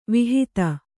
♪ vihita